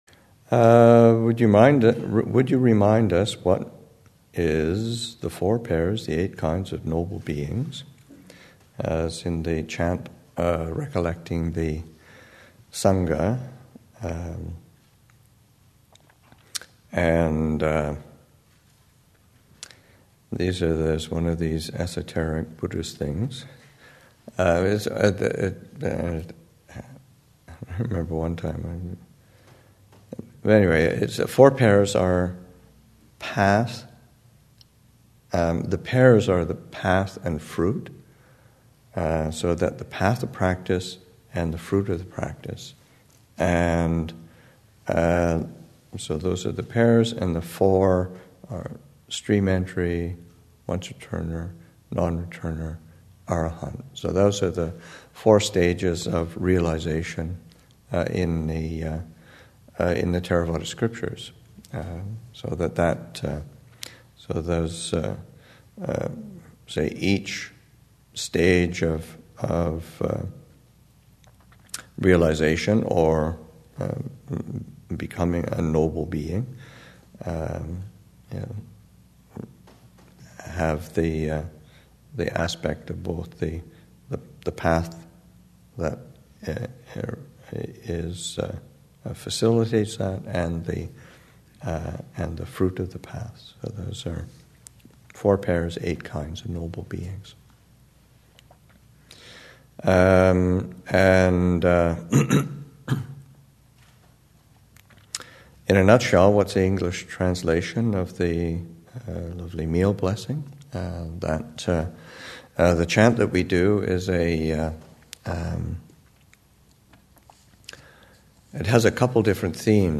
2015 Thanksgiving Monastic Retreat, Session 3 – Nov. 23, 2015